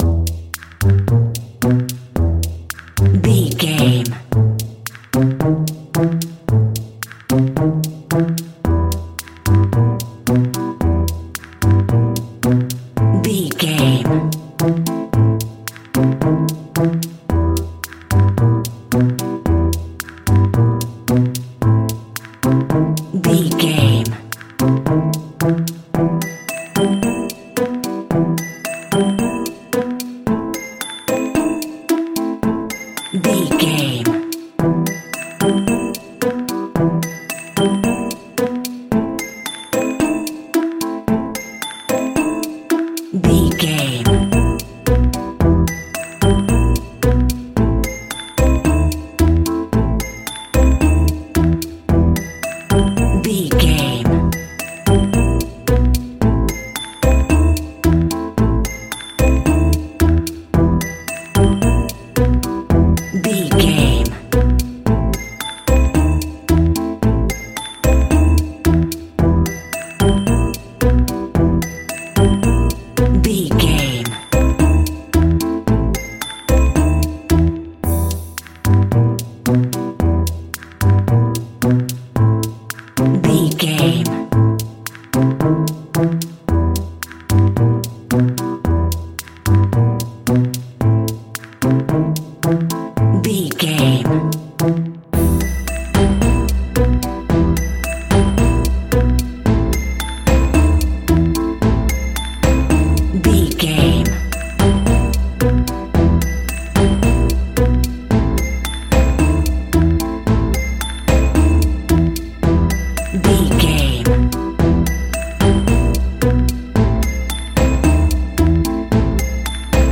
Aeolian/Minor
ominous
eerie
piano
drums
synthesizer
spooky
instrumentals
horror music